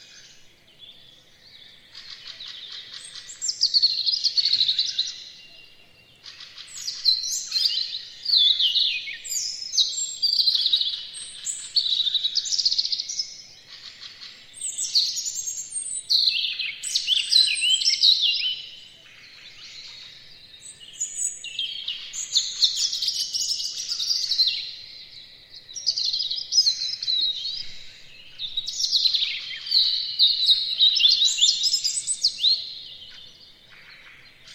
• robin chirping.wav
robin_chirping_u2b.wav